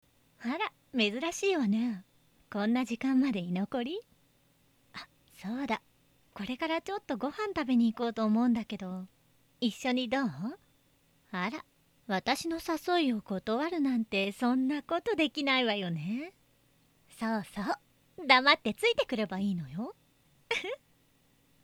サンプルボイス≪44100Hz/16bit/モノラル≫
お姉さん〜大人